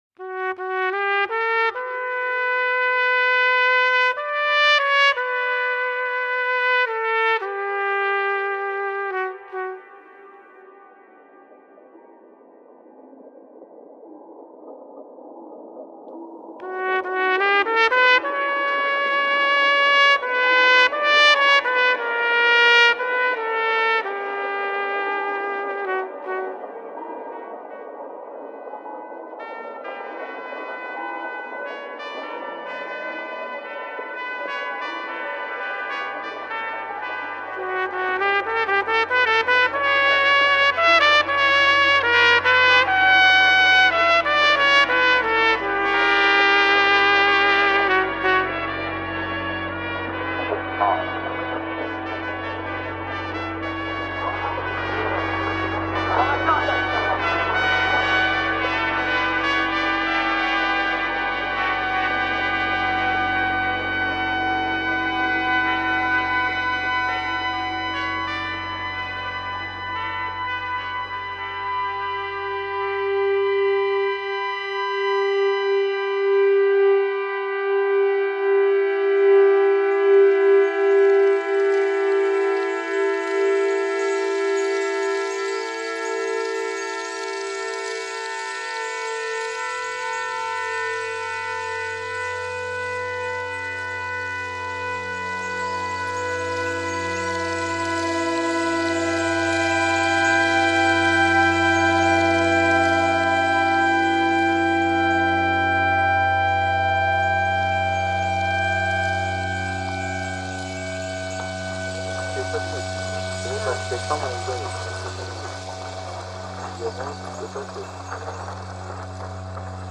The piece begins with a trumpet playing a melody reminiscent of the Adhan, the Muslim call to prayer.